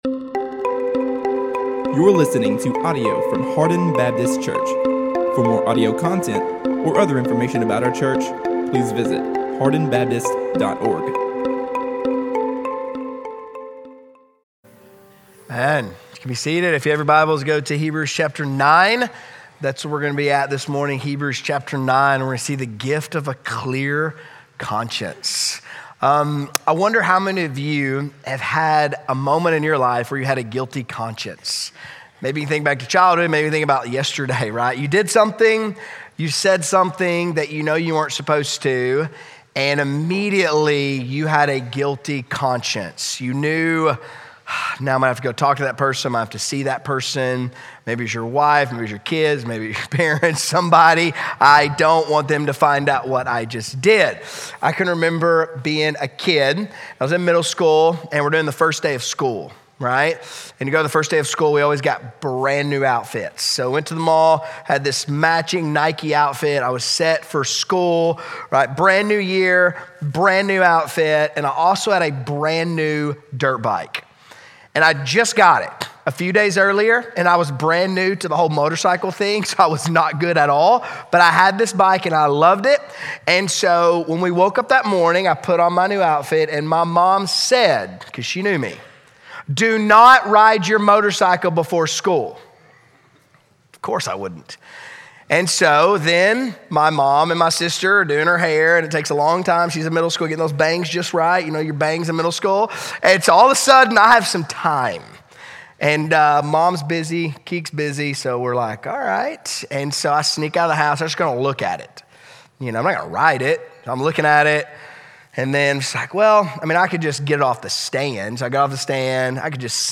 A message from the series "The Life of Christ."